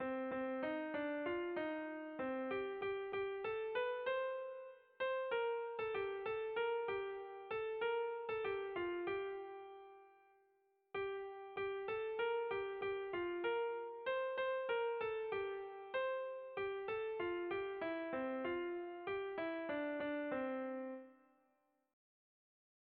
Irrizkoa
Zortziko txikia (hg) / Lau puntuko txikia (ip)
A-B-C-D